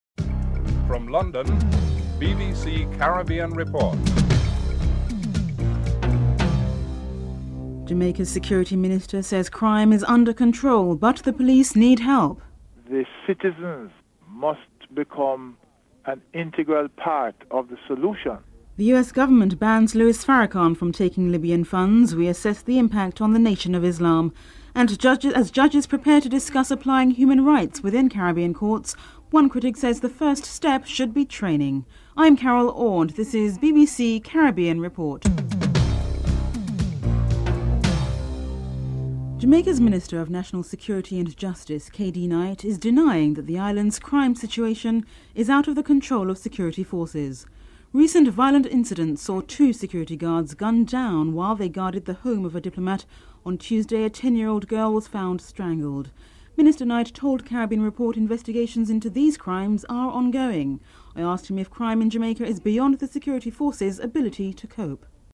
2. Jamaica's Security Minister says crime is under control but the police need help. Jamaican Security Minister Kadi Knight is interviewed (00:38-03:33)